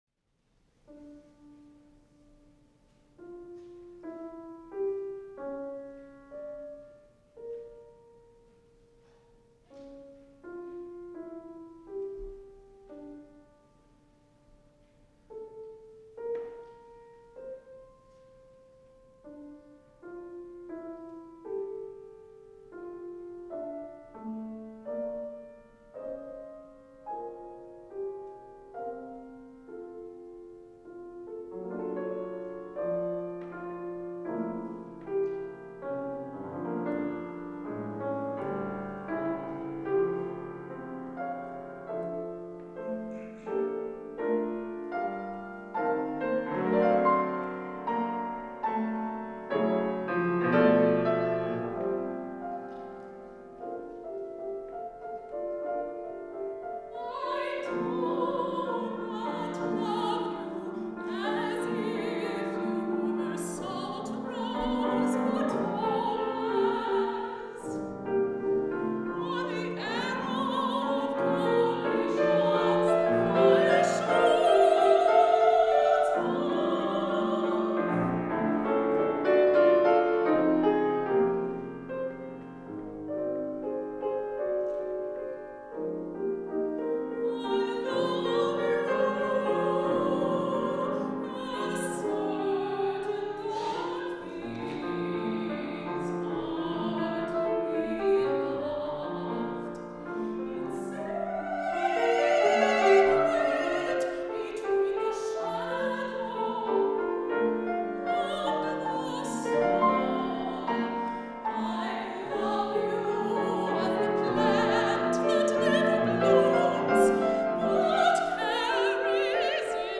Soprano or Mezzo-soprano & Piano (12′)